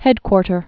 (hĕdkwôrtər)